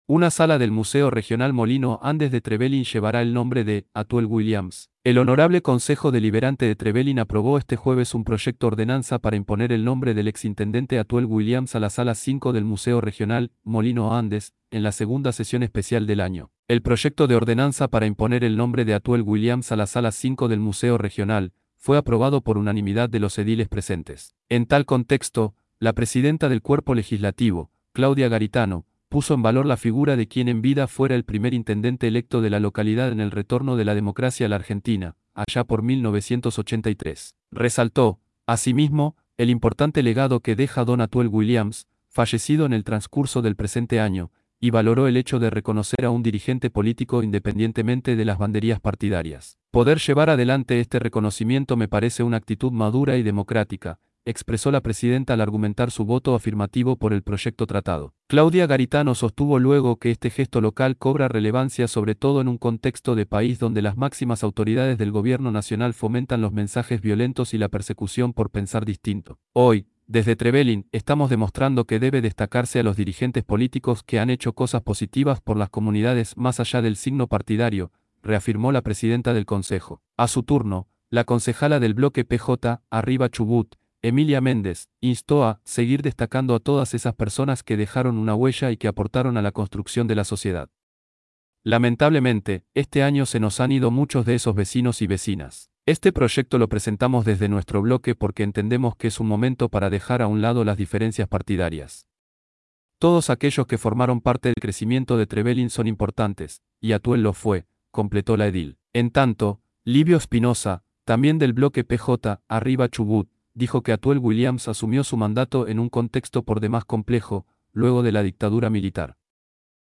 ii_sesion_especial_hcd_2024.mp3